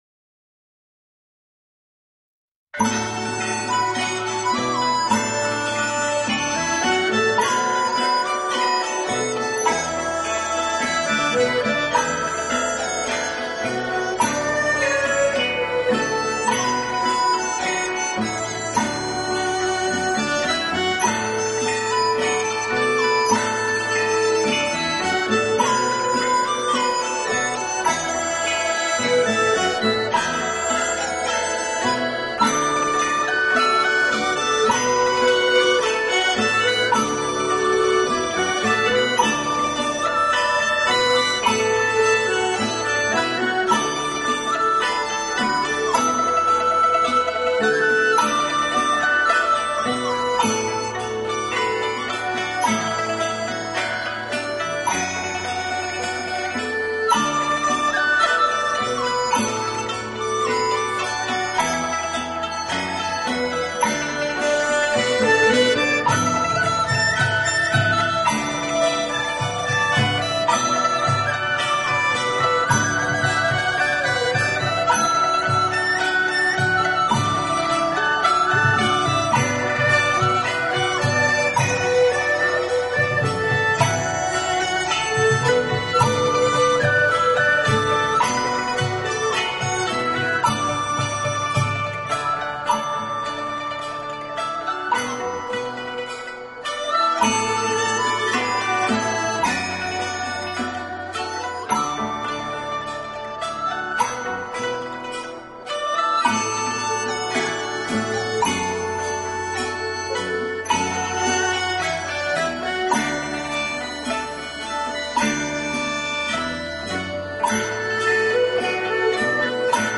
《玄风遗韵》是成都青羊宫道乐团演奏的道乐乐曲。
其传承道乐为广成韵之代表。